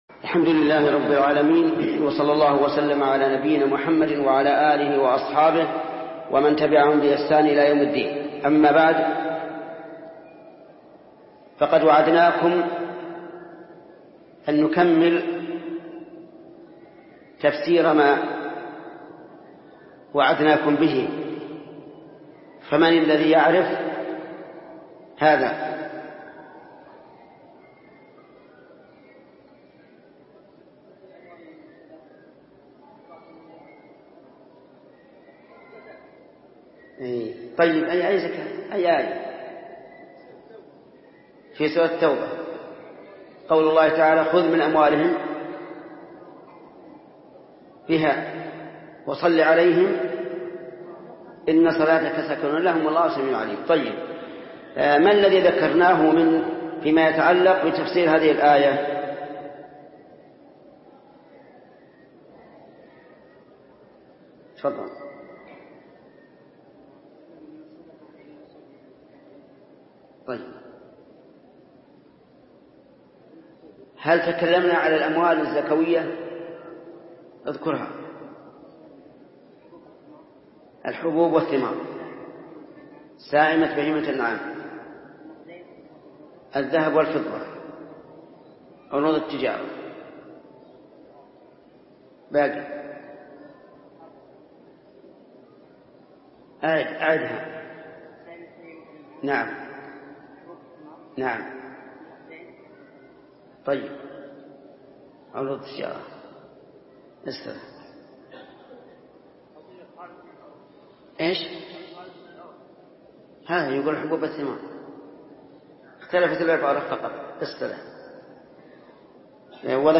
فتاوى ودروس من المسجد الحرام